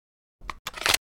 knife_sheath.ogg